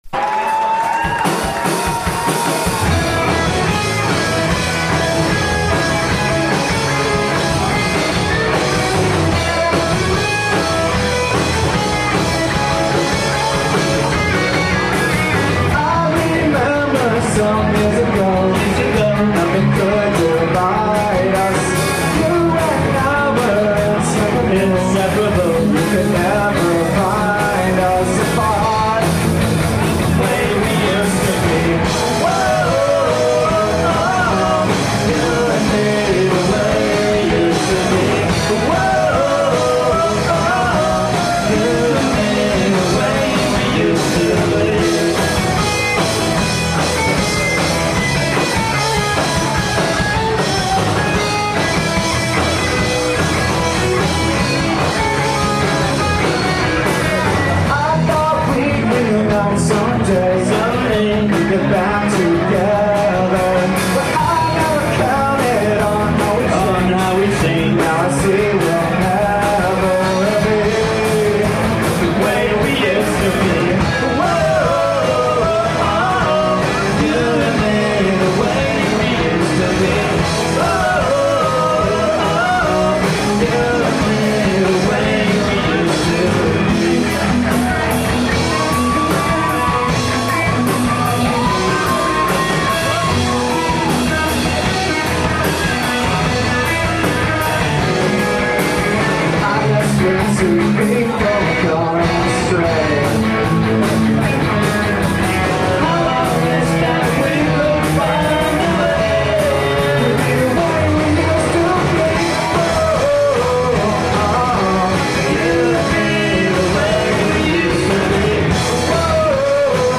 A Boston alt-rock archive, preserved with all its edges.
Live at Copperfield's